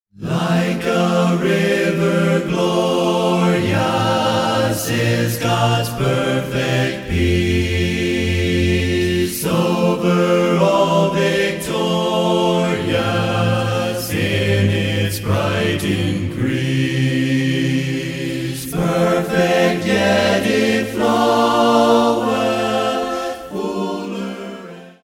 singing 16 hymns a cappella.